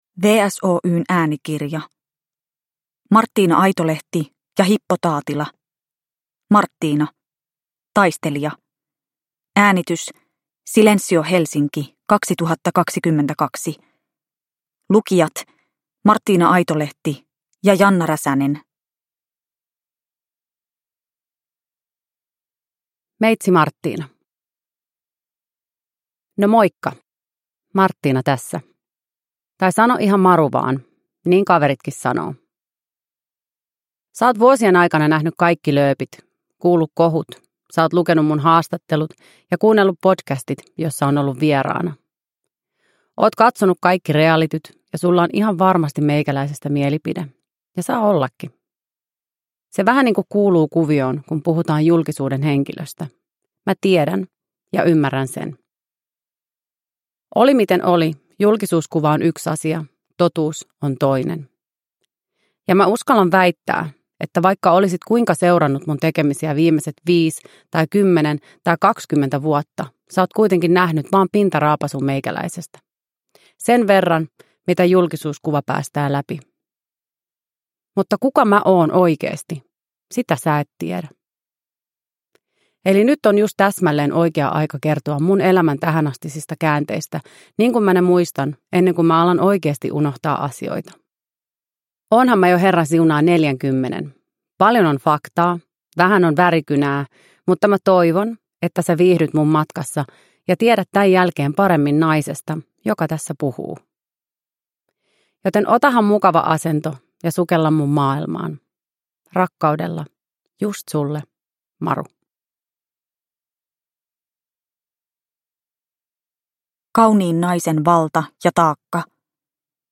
Martina - Taistelija – Ljudbok – Laddas ner